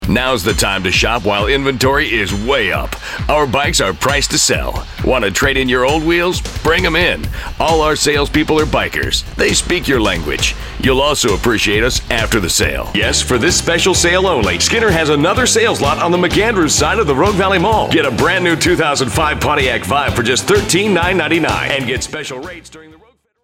Rugged, Powerful, Hard-Sell